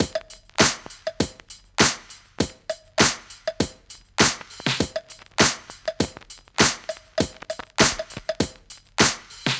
Unison Funk - 1 - 100bpm - Tops.wav